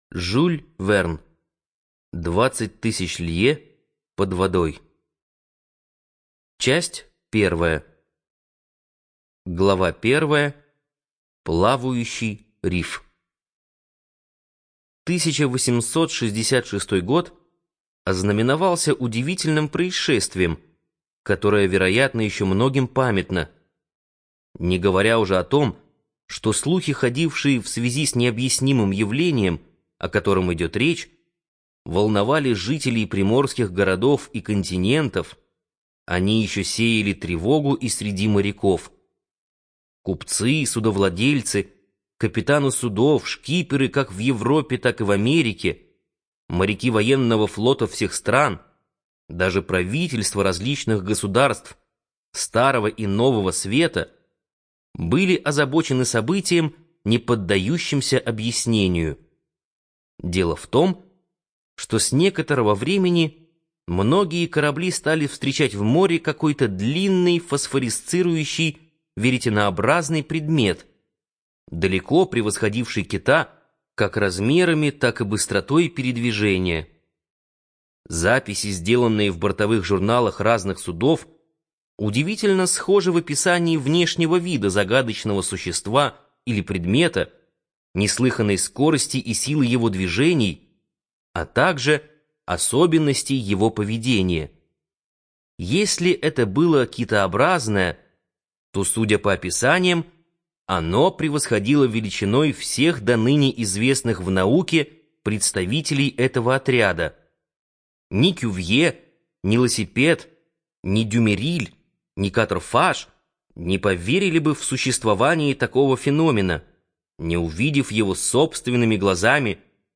ЖанрФантастика
Студия звукозаписиБиблиофоника